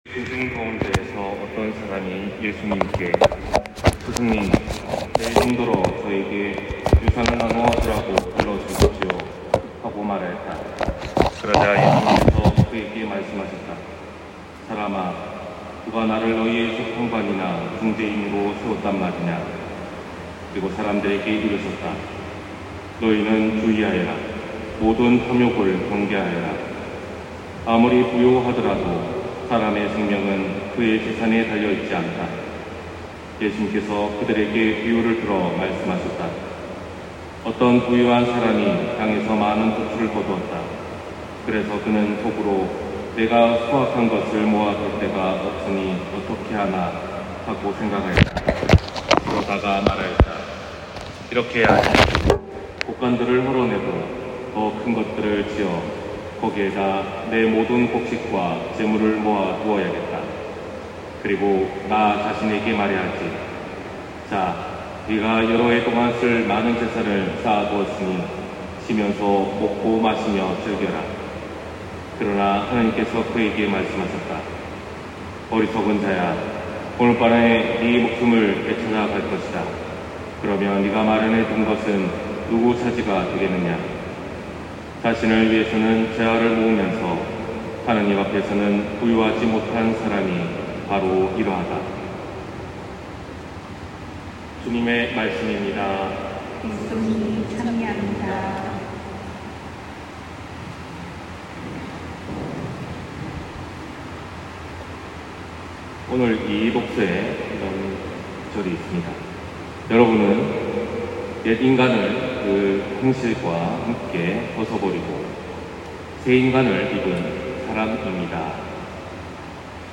250802신부님 강론말씀